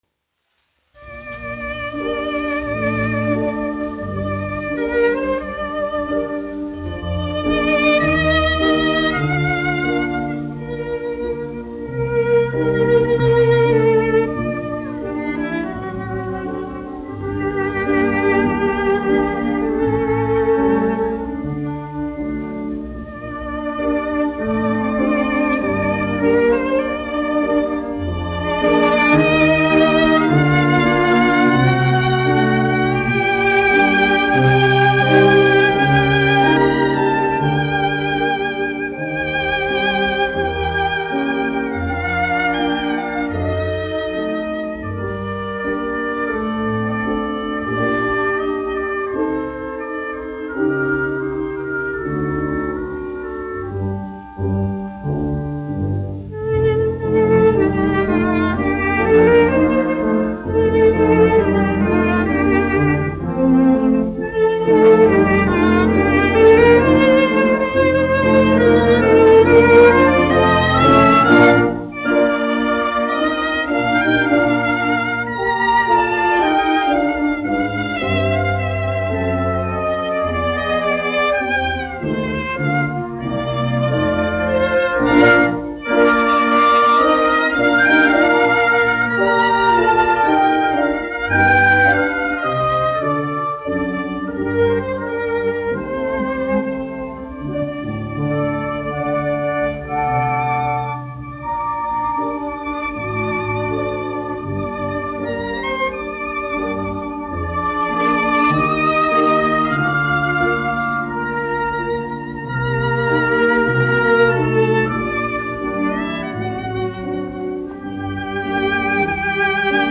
1 skpl. : analogs, 78 apgr/min, mono ; 25 cm
Orķestra mūzika, aranžējumi
Sēru mūzika
Skaņuplate